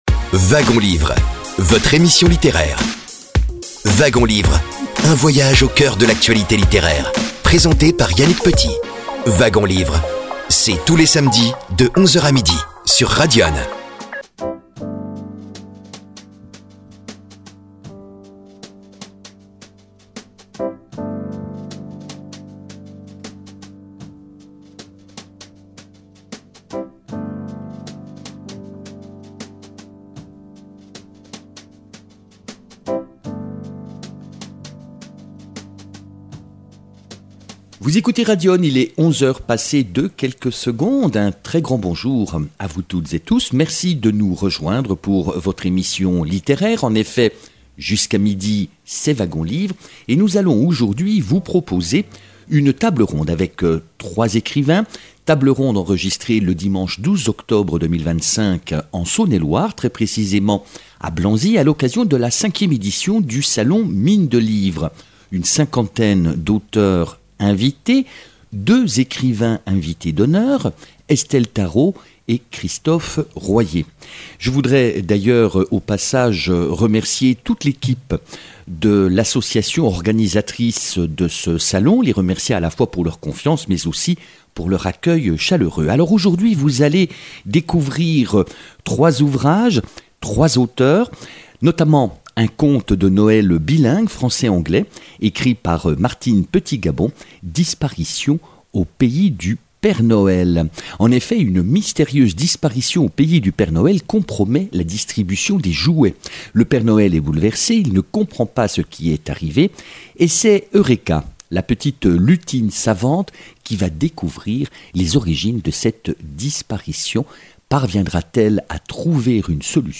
Diffusion de la table ronde enregistrée en public lors du salon du livre de Blanzy (71)